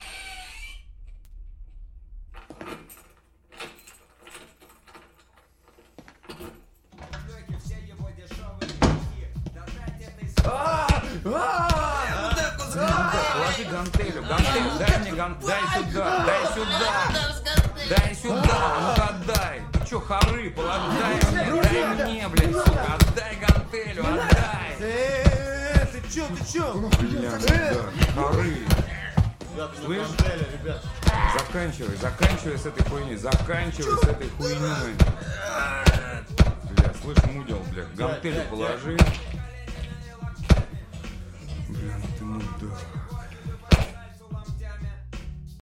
Материал был записан с весны по осень 2007 года в Москве.